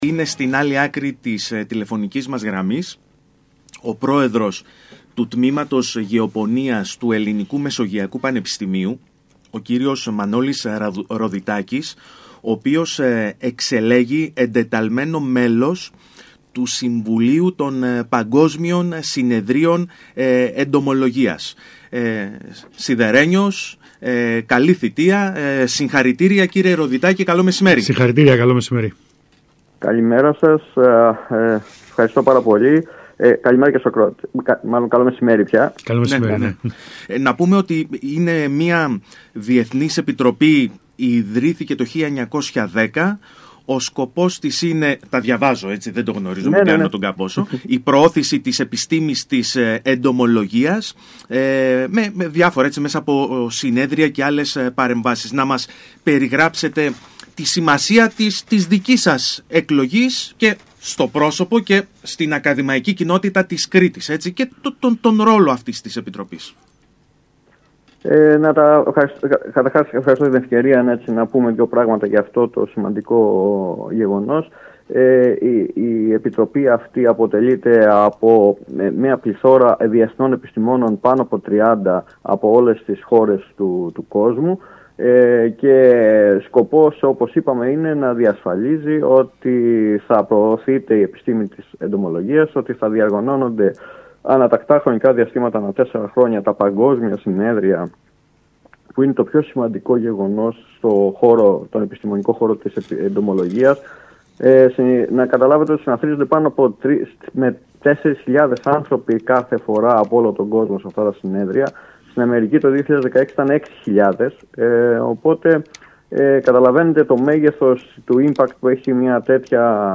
Για την τροποποίηση του προγράμματος δακοπροστασίας στην Κρήτη, όπως προέκυψε από την εμπειρία των ακραίων φαινομένων του 2019, μίλησε στο ραδιόφωνο του ΣΚΑΪ Κρήτης